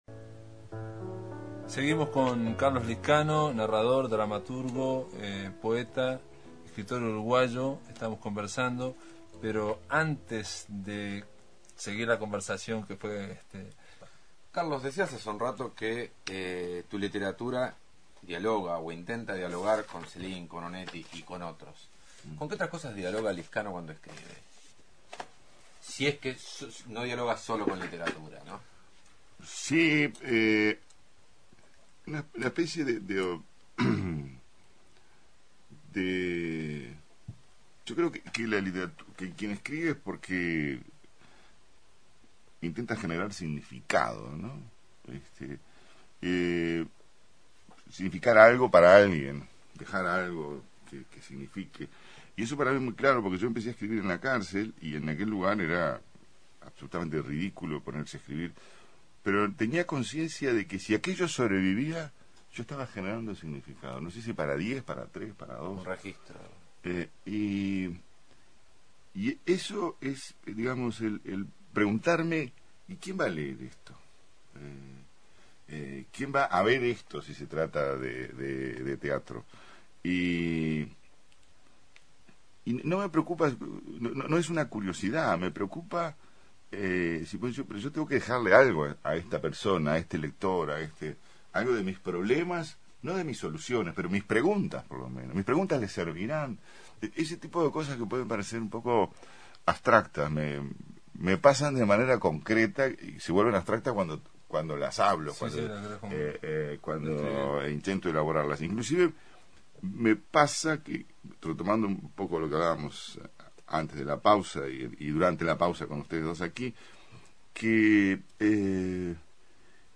En El sótano de la Máquina rescatamos una entrevista a Carlos Liscano, de agosto de 2005, realizada en Sopa de Letras.